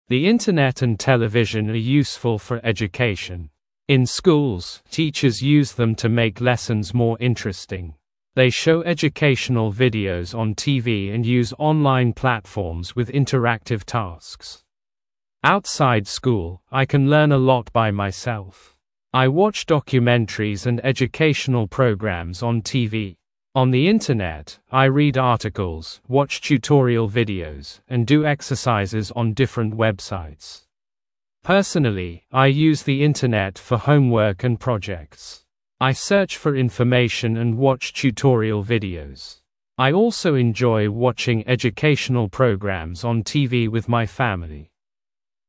Произношение: